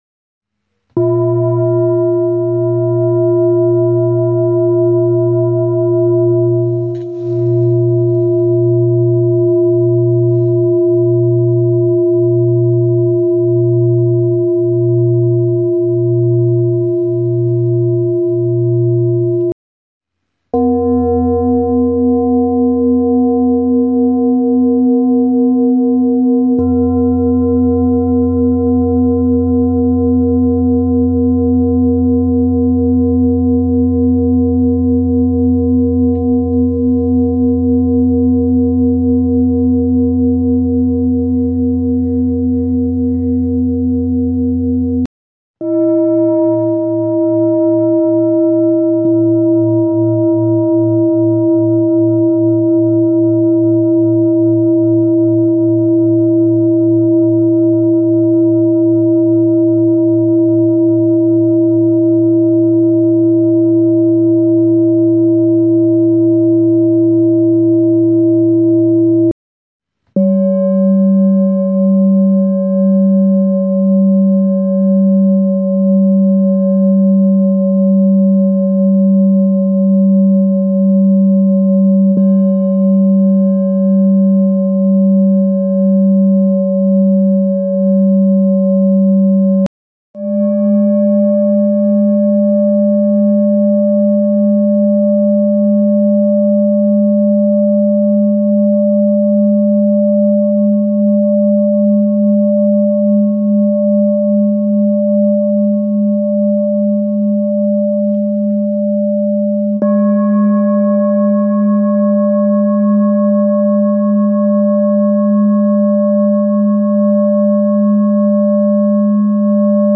7 Size Sync Set of High Quality Peter Hess Singing Bowls, Hand Hammered Clean Finishing, Select Accessories , A bowl used for meditation and healing, producing a soothing sound that promotes relaxation and mindfulness
Singing Bowl Ching Lu Kyogaku
Material 7 Metal Bronze
Tibetan bowls emit very pure tones, close to sine waves.